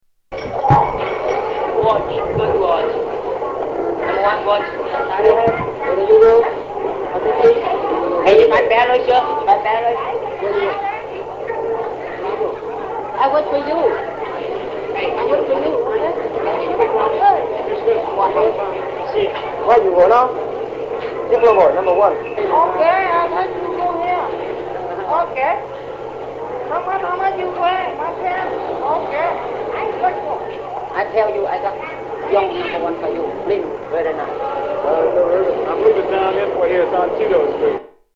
Street life in Saigon